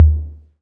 • Reverb Tom Drum D# Key 08.wav
Royality free tome drum sample tuned to the D# note. Loudest frequency: 104Hz
reverb-tom-drum-d-sharp-key-08-cQw.wav